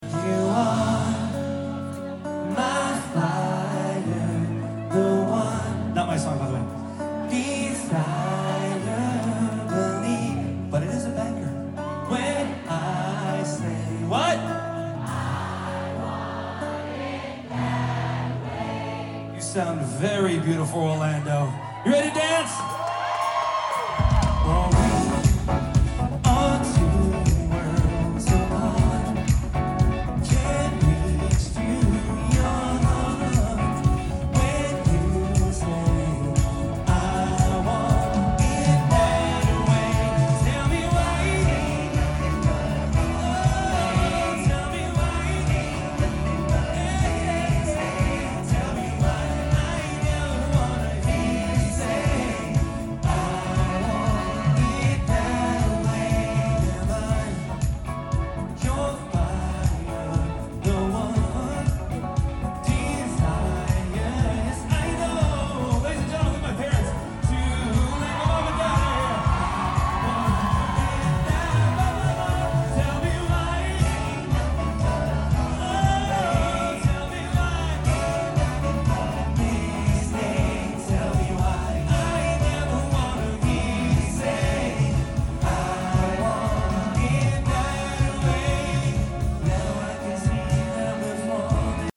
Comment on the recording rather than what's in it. at Epcot in Walt Disney World